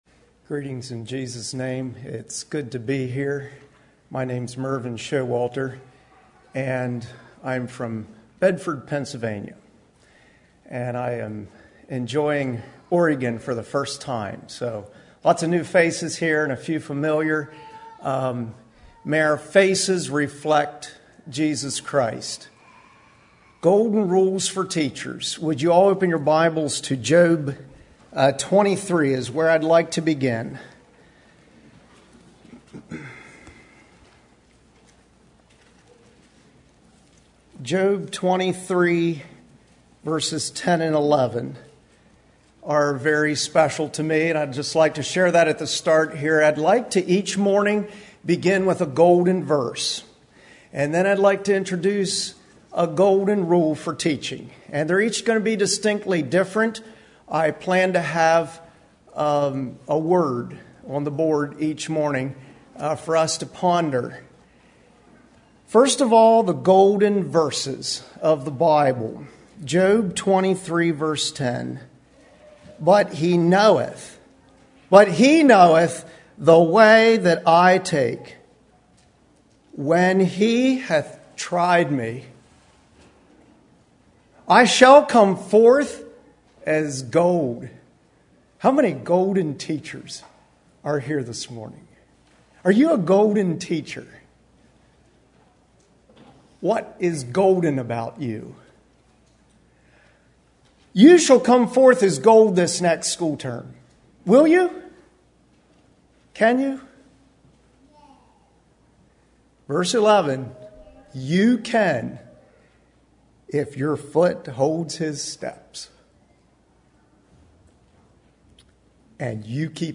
Home » Lectures » Golden Rules for Teachers, Part 1
Western Fellowship Teachers Institute 2023